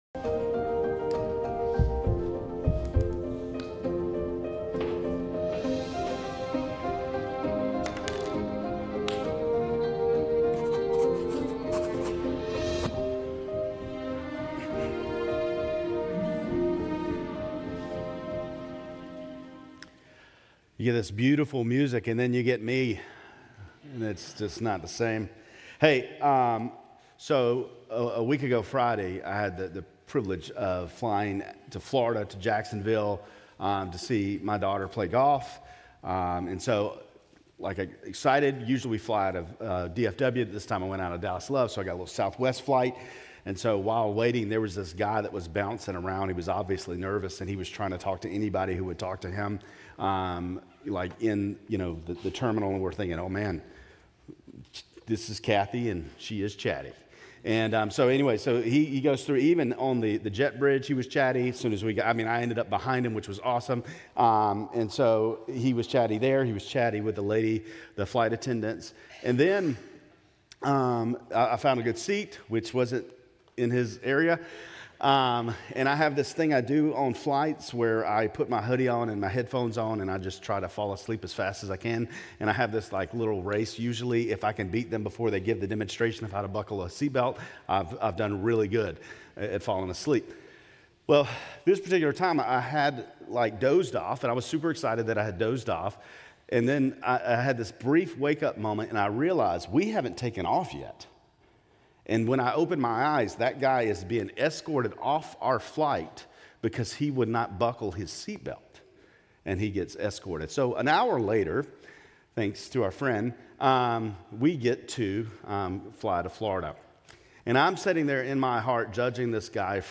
Grace Community Church Lindale Campus Sermons 2_16 Lindale Campus Feb 17 2025 | 00:23:04 1x 00:00 / 00:23:04 Subscribe Share RSS Feed Share Link Embed